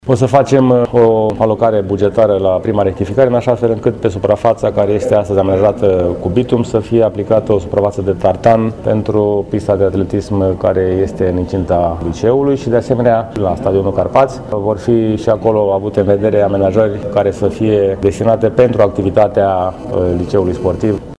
Pentru a îndeplini funcțiile unei unități de învățământ cu accent pe pregătire fizică și sport, noua locație dar și stadionul Carpați, din imediata vecinătate, vor avea nevoie urgentă și de alte dotări sau îmbunătățiri, la care s-a referit primarul municipiului Brașov, George Scripcaru